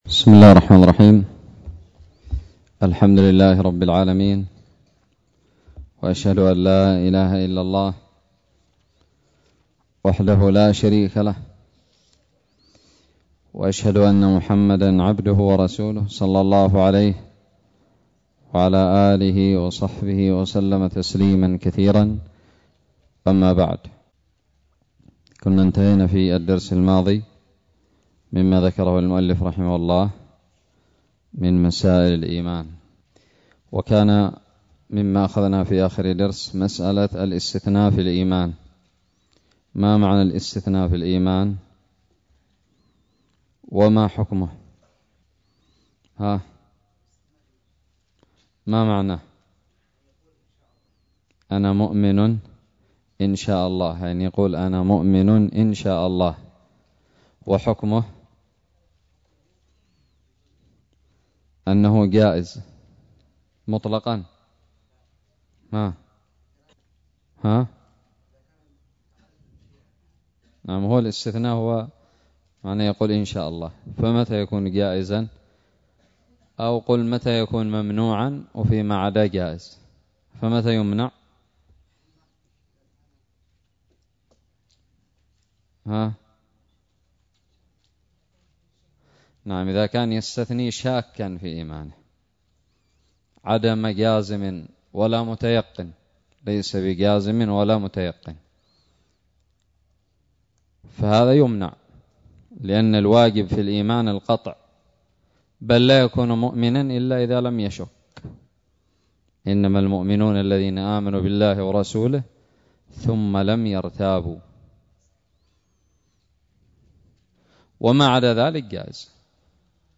الدرس الثاني والثلاثون من شرح كتاب الاقتصاد في الاعتقاد للمقدسي
ألقيت بدار الحديث السلفية للعلوم الشرعية بالضالع